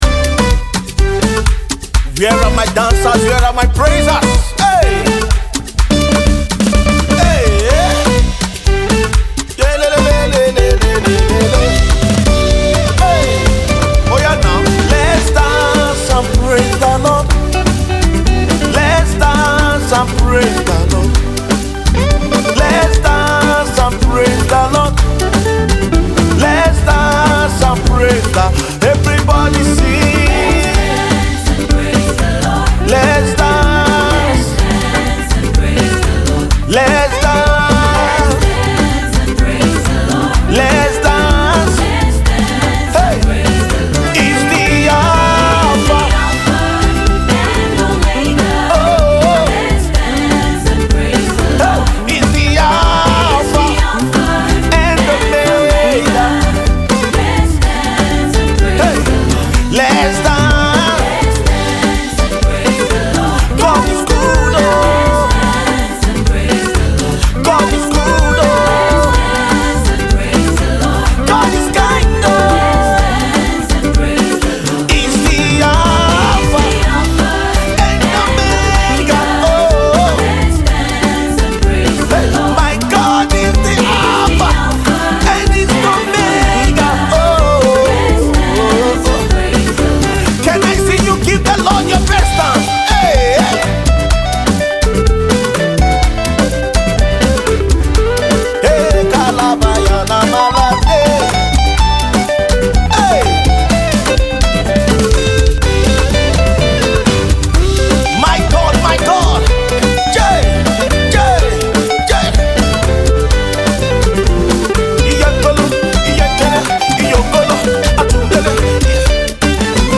Gospel MusicVideo